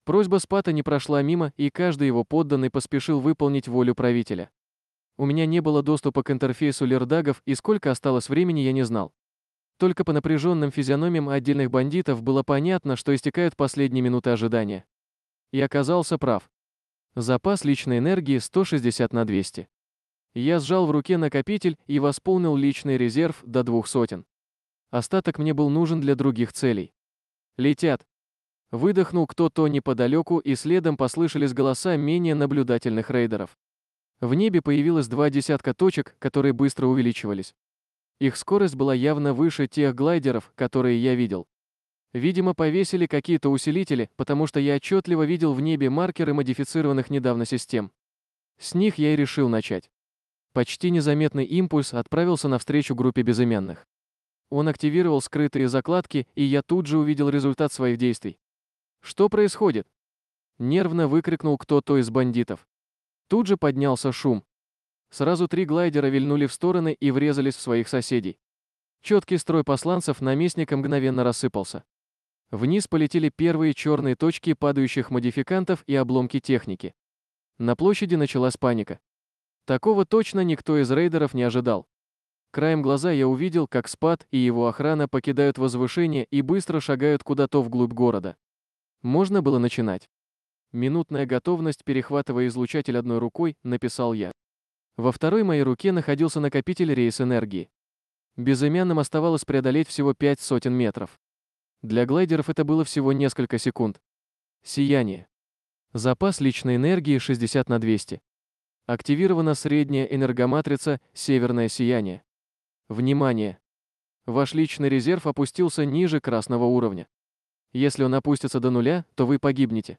Аудиокнига Убивать, чтобы жить 2
Качество озвучивания весьма высокое.